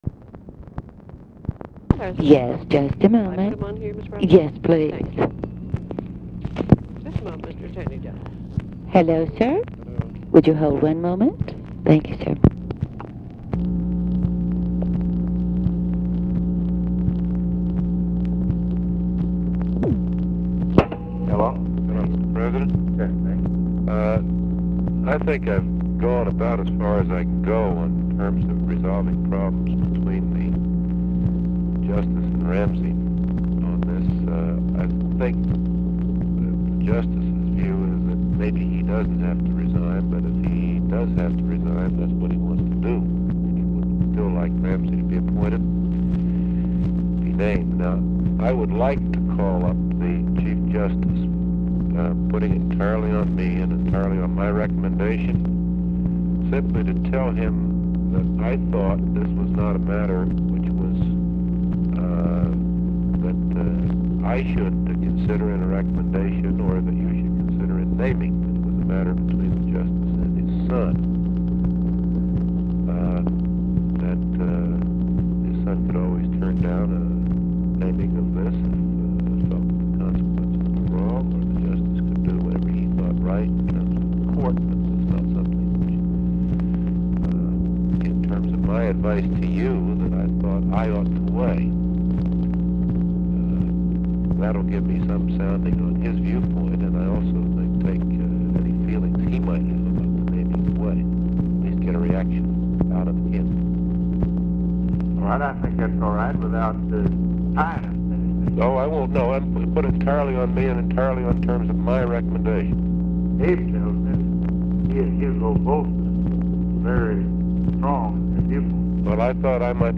Conversation with NICHOLAS KATZENBACH, September 26, 1966
Secret White House Tapes